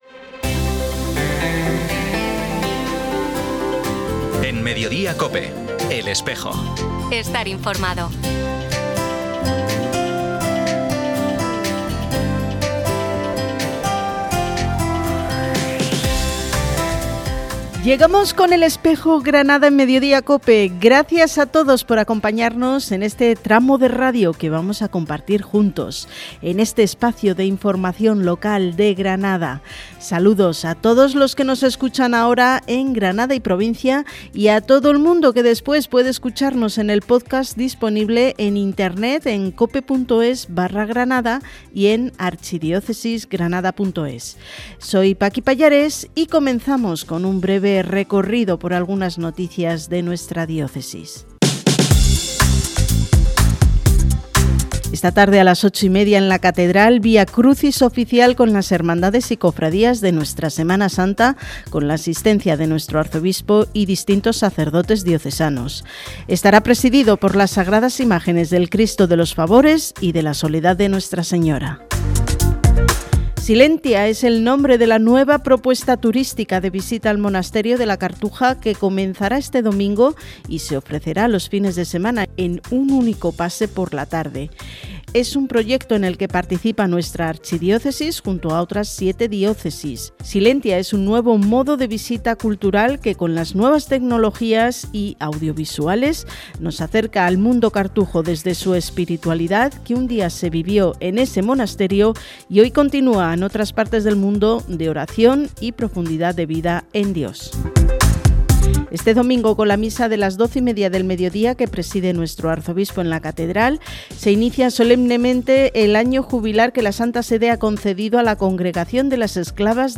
Programa emitido en COPE Granada, el 27 de febrero de 2026.